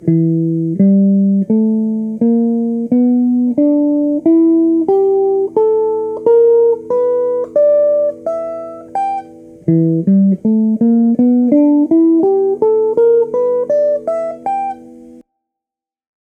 Audio de la ligne de basse + l'accompagnement :
Tous les coups de médiators sont joués vers le bas.